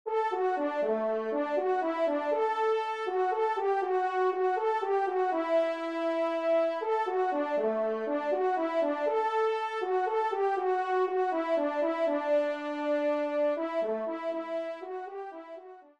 Trompe Solo (TS)